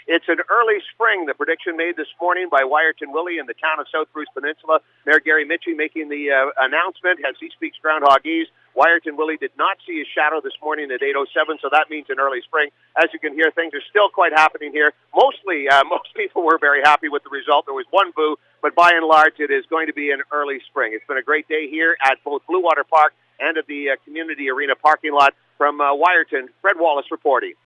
Hundreds of people were on hand outside the Wiarton Arena for Willie’s prediction this morning, in a return to an in-person event after a two-year absence.
willie-fww-call.mp3